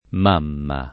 m#mma] s. f. — d’uso region., sempre senz’art. e per lo più come vocativo, mammà [mamm#+] (raro mamà [mam#+]) — solo mamma se seguìto da nome proprio (es. m. Rosa), se detto di animali (es. l’agnellino e la sua m.), se usato nel senso etimol. (poet. «mammella») o in uno dei sensi fig. (es.: De l’Eneida dico, la qual mamma Fummi [